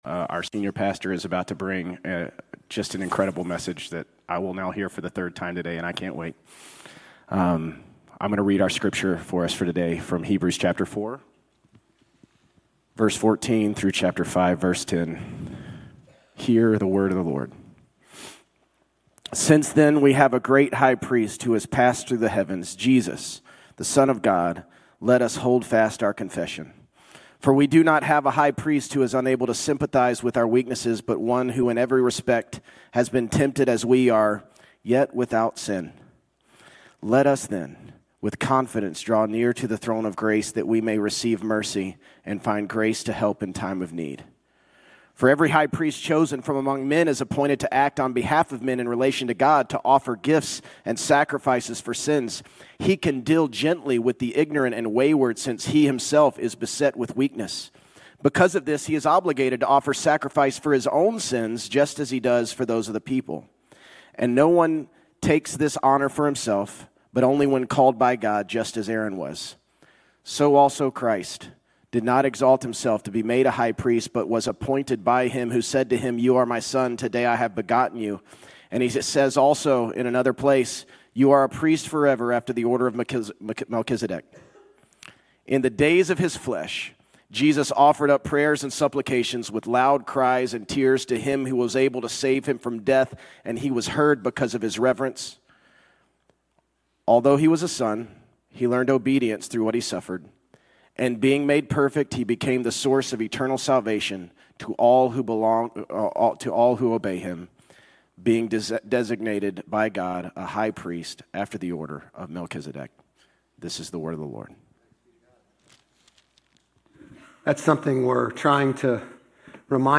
Grace Community Church Old Jacksonville Campus Sermons 10_19 Old Jacksonville Campus Oct 20 2025 | 00:41:02 1x 00:00 / 00:41:02 Subscribe Share RSS Feed Share Link Embed